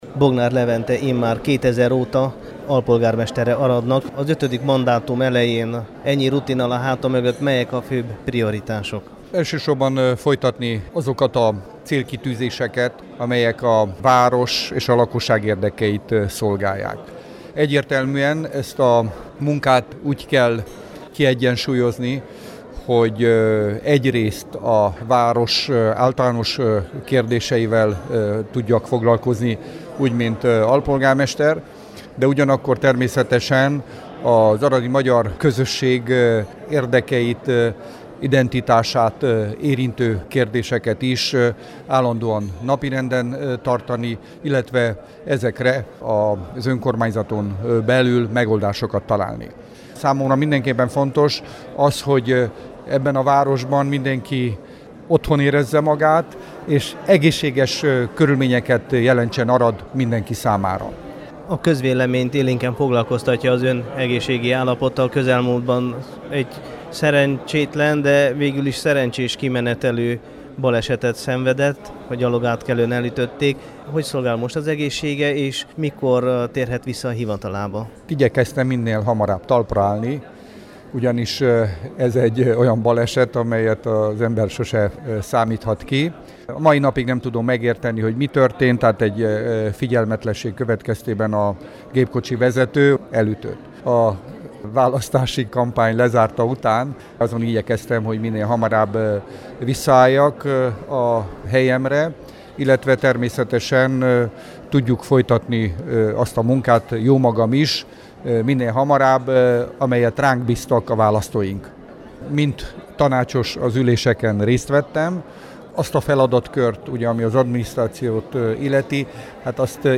A helyi tanács tagjainak eskütétele után szólaltatta meg a két RMDSZ-es képviselőt